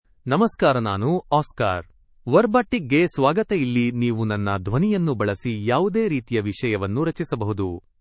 OscarMale Kannada AI voice
Voice sample
Listen to Oscar's male Kannada voice.
Male
Oscar delivers clear pronunciation with authentic India Kannada intonation, making your content sound professionally produced.